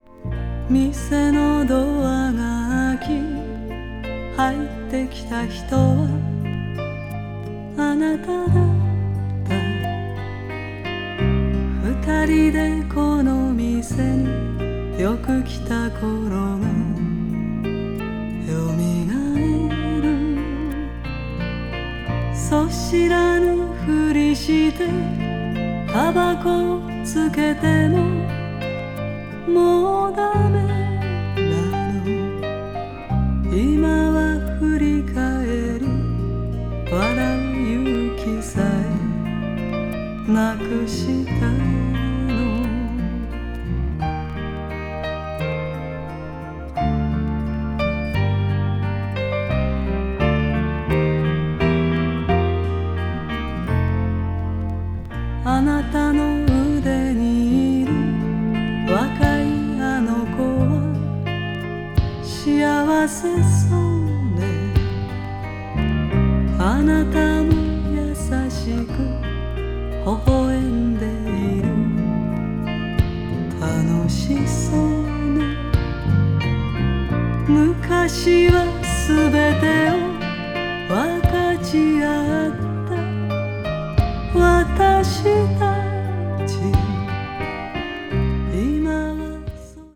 adult pop   japanese pop   kayohkyoku   mellow groove